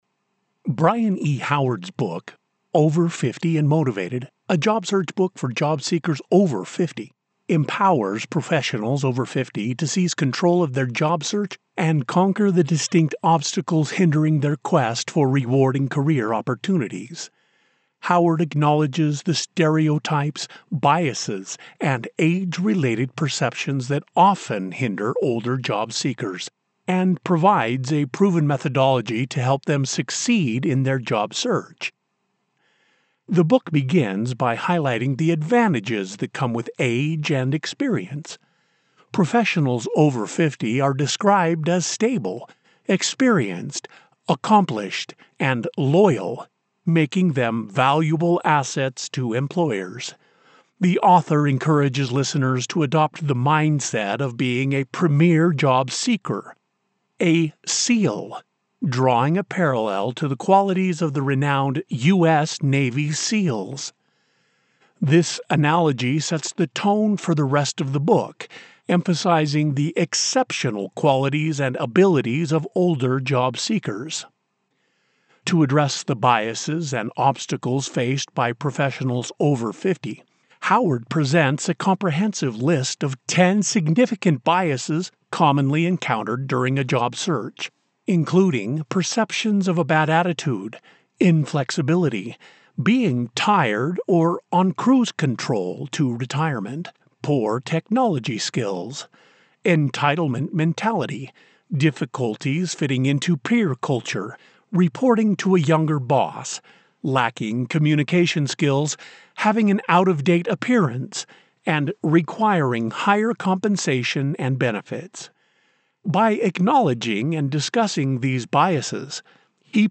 Rich • Robust • Trustworthy
A warm embrace of fatherly wisdom.
Non-Fiction • Self-Help
Mid-West American